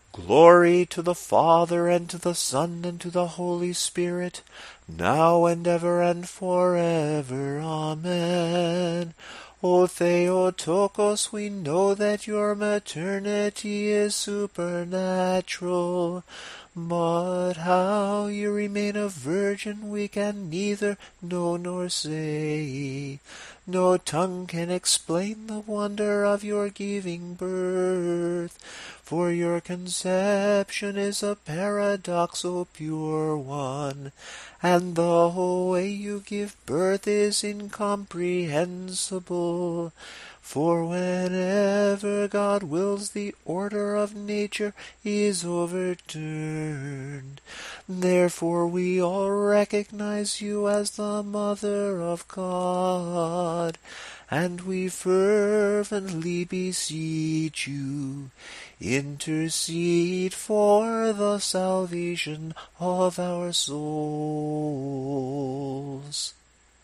The last sticheron at the Lamplighting Psalms on an ordinary Saturday evening is called a dogmatikon, because it highlights the dogma or teaching of the Incarnation. Here is the tone 7 dogmatikon, set to the Tone 7 samohlasen melody. The verse is given, followed by the sticheron.
Tone_7_samohlasen_dogmatikon.mp3